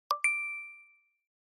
Apple Pay Success Sound Effect Download: Instant Soundboard Button
Apple Pay Success Sound Button - Free Download & Play